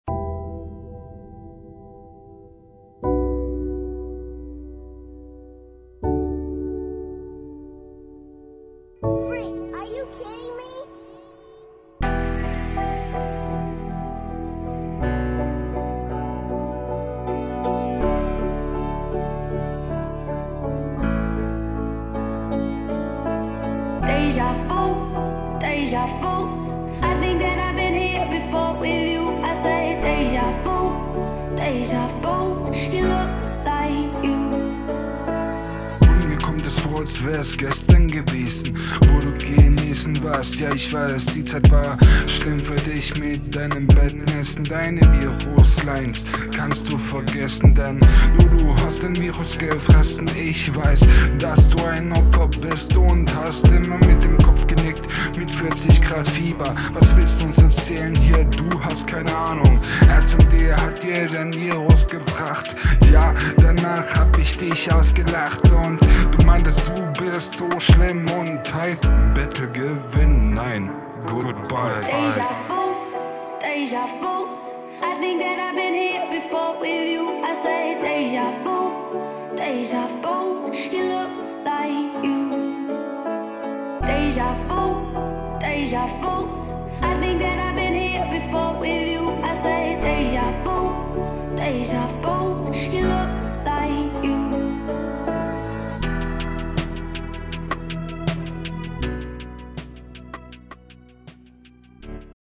Flow: ➨ Kommst stimmlich schon besser auf dem Beat ➨ An sich ist der Flow …